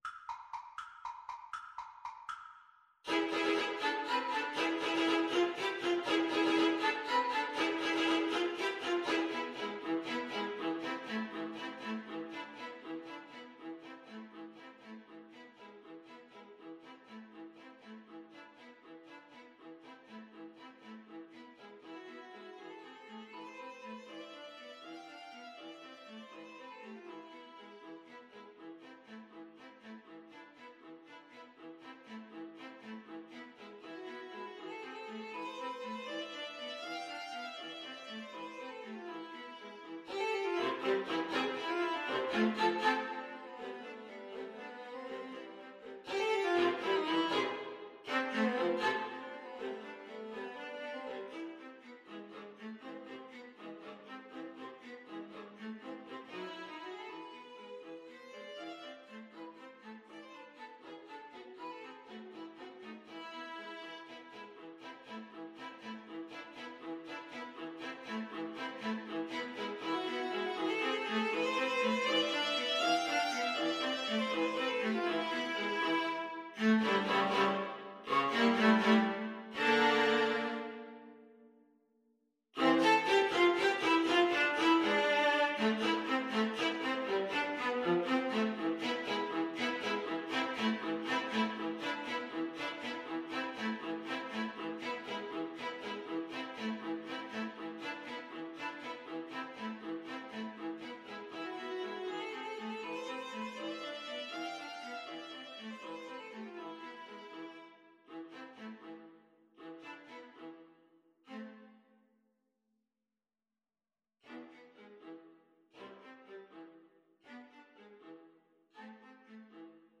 Allegro vivo (.=80) (View more music marked Allegro)
Viola Trio  (View more Advanced Viola Trio Music)